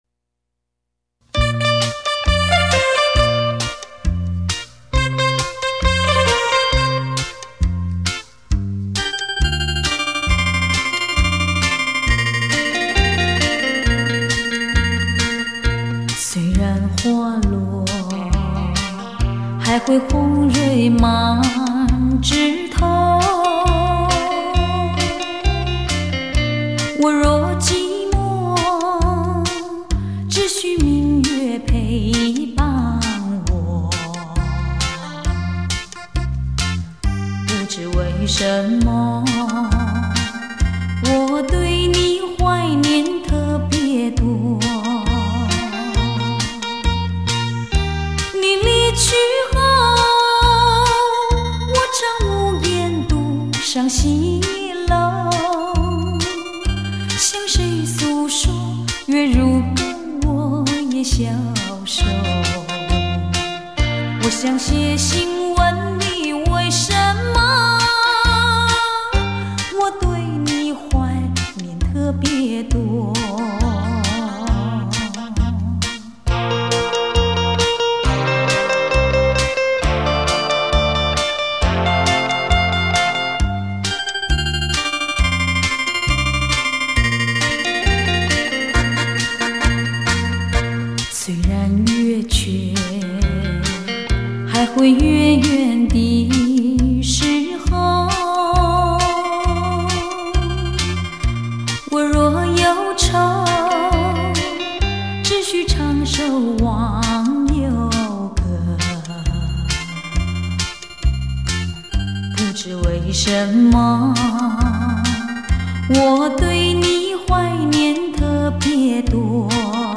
吉鲁巴